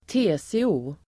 Uttal: [²t'e:se:o:]